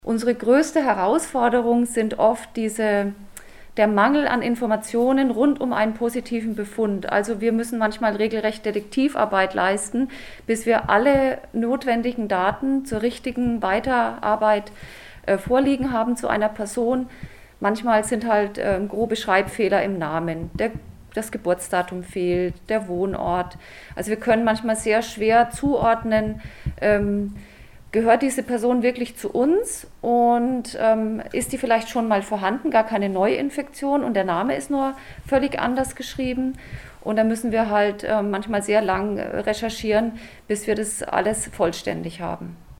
Besuch im Gesundheitsamt Schweinfurt- Alle Interviews und Videos zum Nachhören - PRIMATON